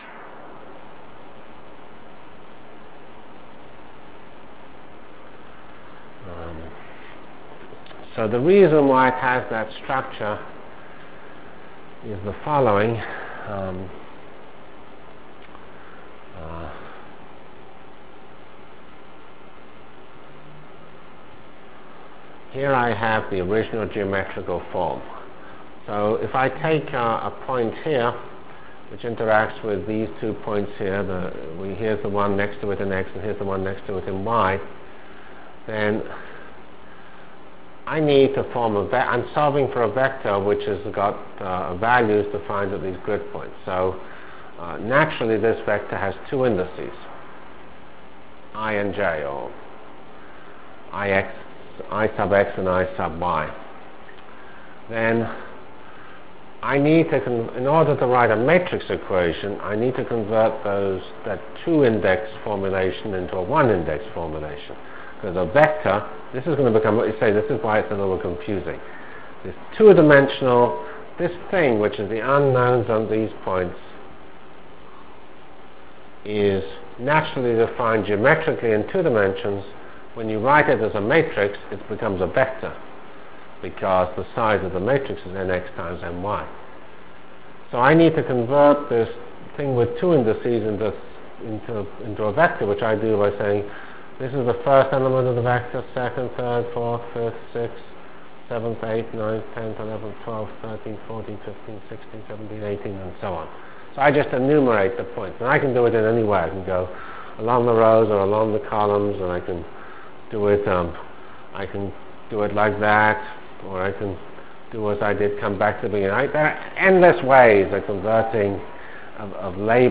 From CPS615-Basic PDE Solver Discussion and Sparse Matrix Formulation Delivered Lectures of CPS615 Basic Simulation Track for Computational Science -- 8 November 96. by Geoffrey C. Fox *